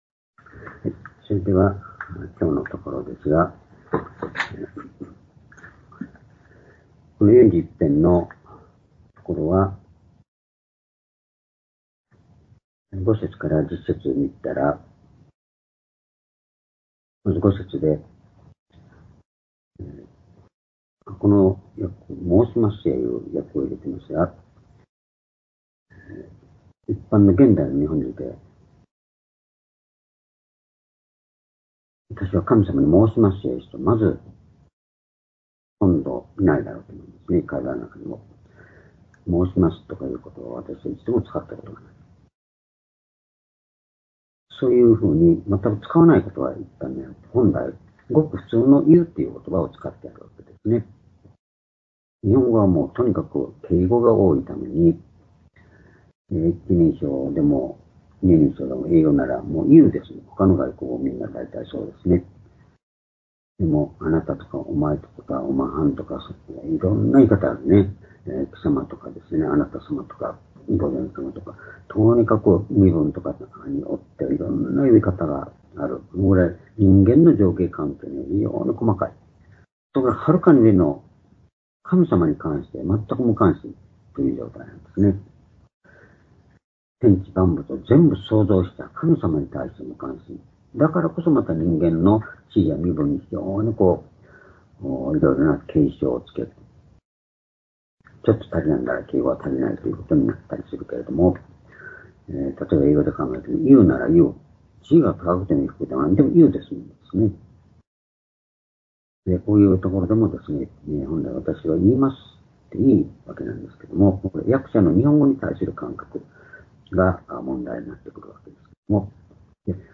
（主日・夕拝）礼拝日時 2024年4月16日(夕拝) 聖書講話箇所 「悪との闘いにおける罪の赦し」 詩篇41編 5節～10節 ※視聴できない場合は をクリックしてください。